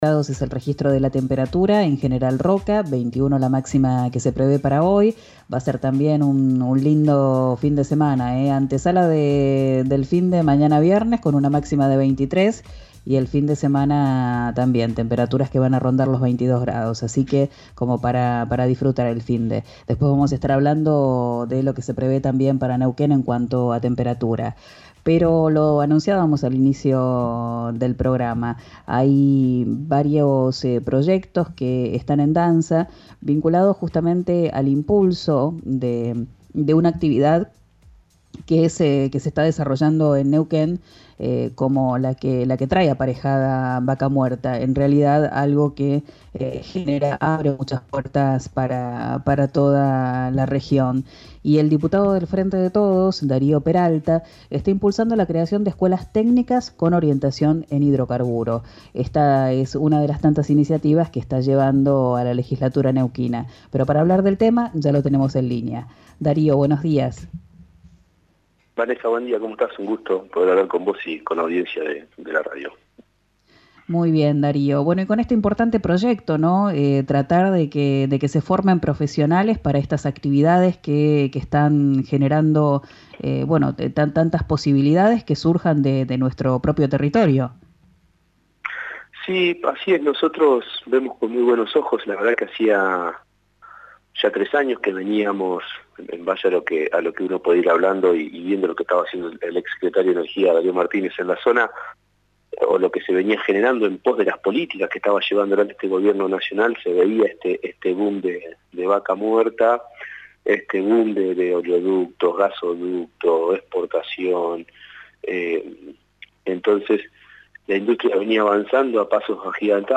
Dario Peralta, diputado provincial por el Frente de Todos, habló con RÍO NEGRO RADIO sobre sus iniciativas en la Legislatura de Neuquén. Propuso la creación de dos escuelas técnicas con orientación en hidrocarburos, para que los y las jóvenes puedan insertarse en la industria petrolera cuando terminen de estudiar.